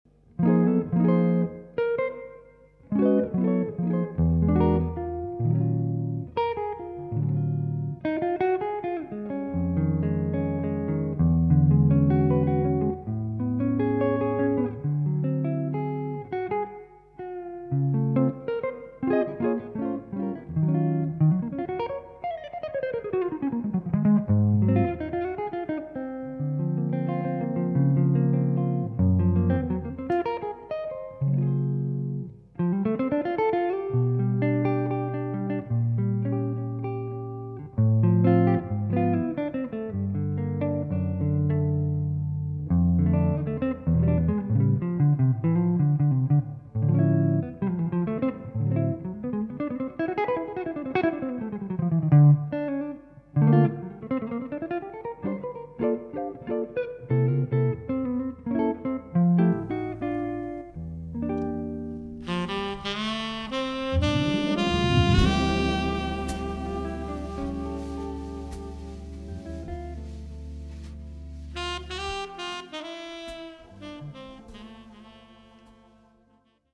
hammond